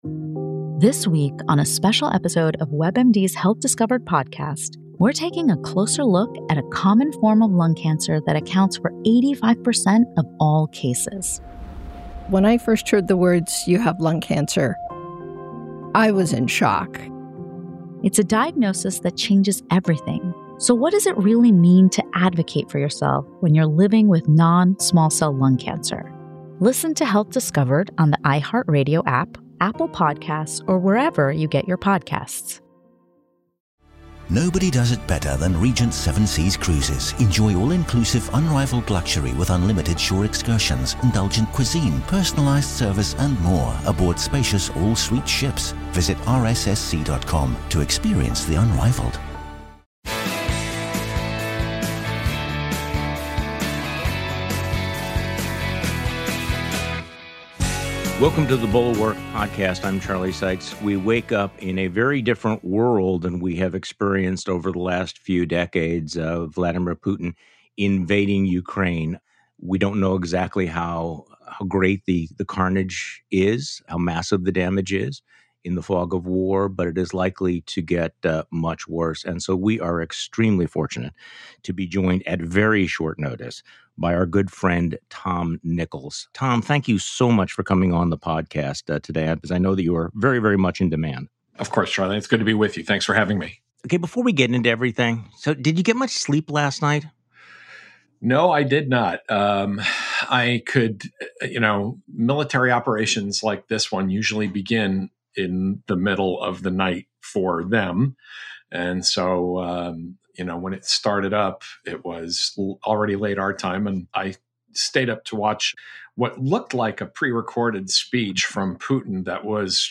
Tom Nichols joins Charlie Sykes on today's podcast.